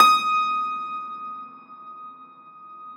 53e-pno19-D4.wav